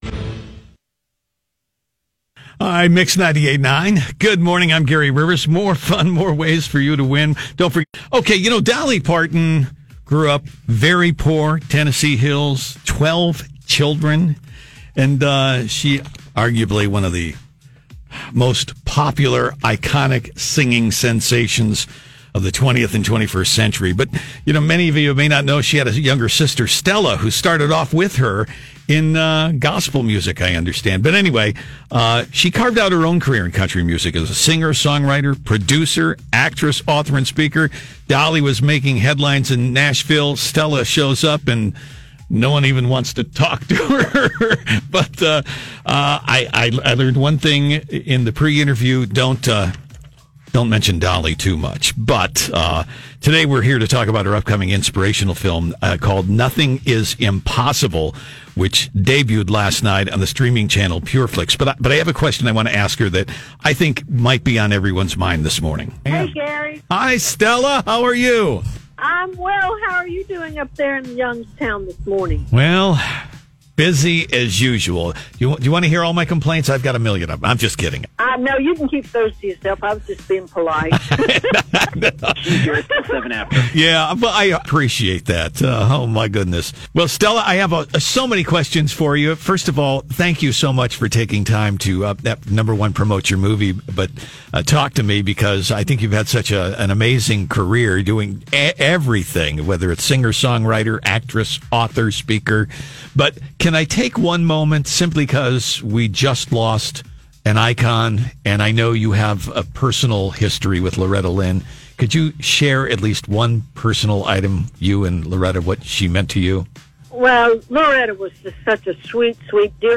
Stella Parton, younger sister of Dollar Parton, who carved out her own career in countrymusic…as a singer, songwriter, producer, actress author and speaker…was a guest on the Mix Morning Show today to talk about her inspirational film, NOTHING IS IMPOSSIBLE, which debuted last night on the streaming channel, PURE FLIX.